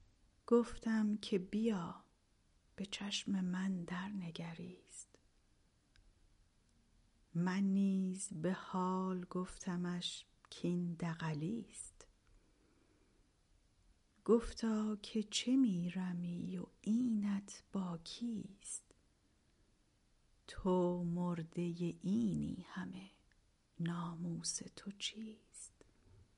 متن خوانش: